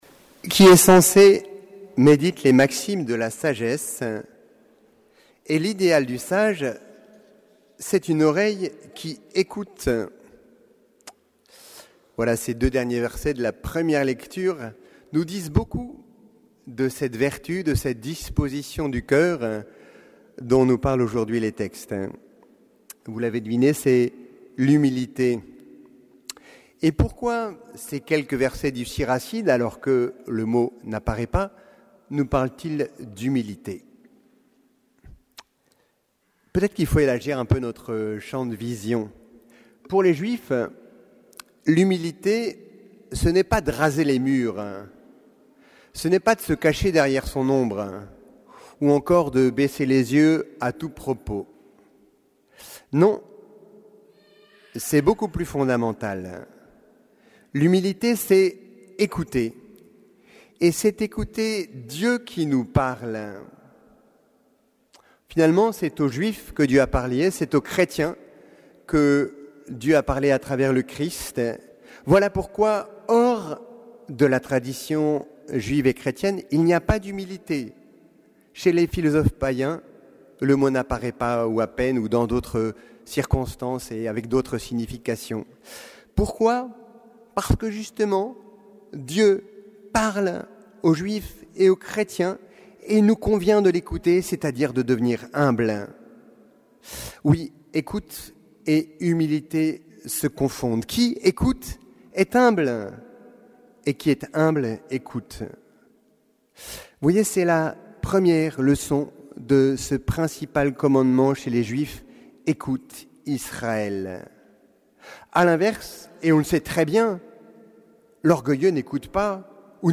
Homélie du 22e dimanche du Temps Ordinaire